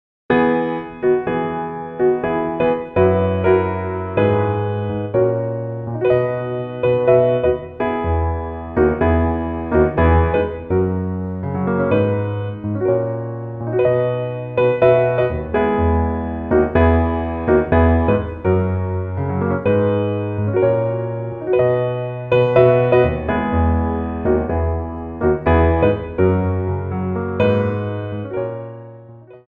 Piano Arrangements of Popular Music
4/4 (16x8)